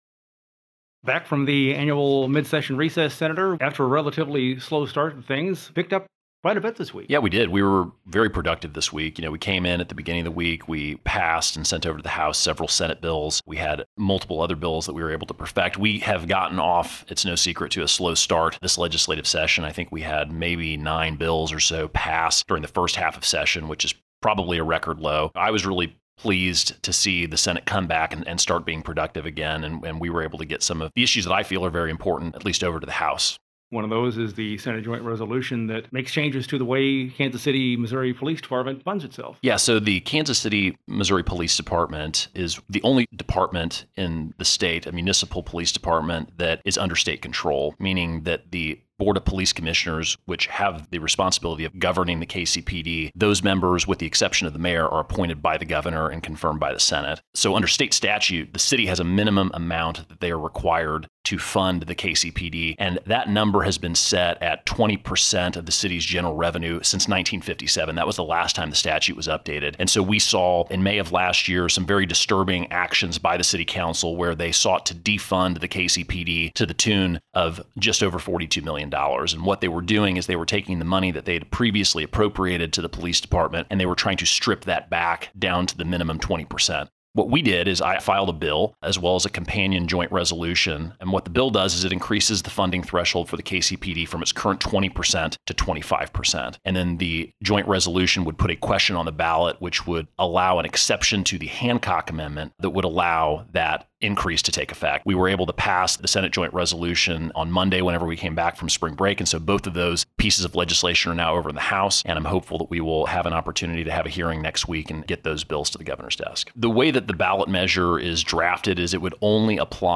JEFFERSON CITY — State Sen. Tony Luetkemeyer, R-Parkville, discusses Senate Joint Resolution 38, which would — upon voter approval — modify constitutional provisions relating to funding for a police force established by a state board of police commissioners. He also talks about Senate Bills 775, 751 & 640, legislation that seeks to modify provisions relating to sexual offenses.